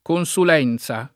consulenza [ kon S ul $ n Z a ] s. f.